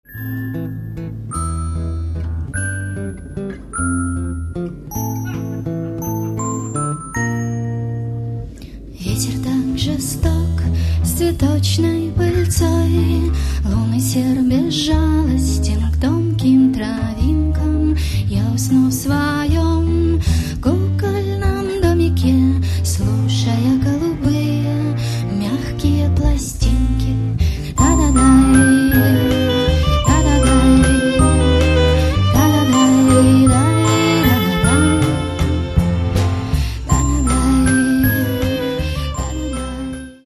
Каталог -> Рок и альтернатива -> Лирический андеграунд
(live)